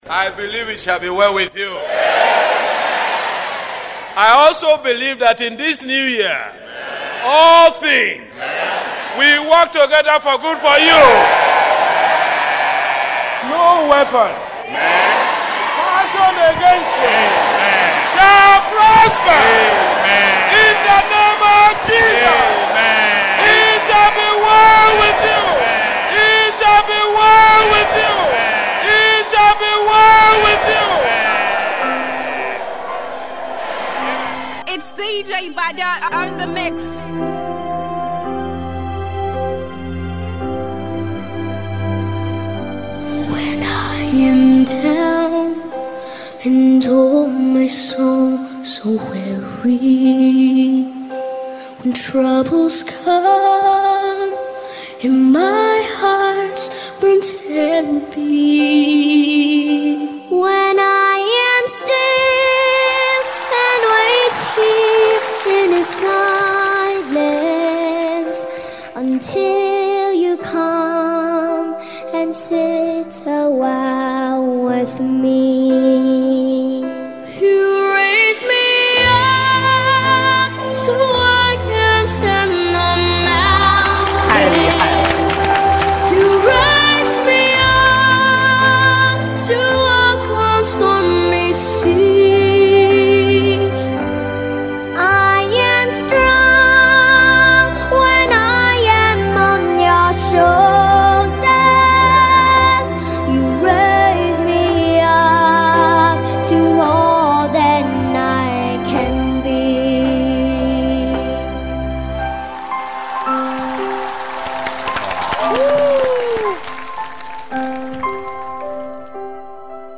gospel mixtape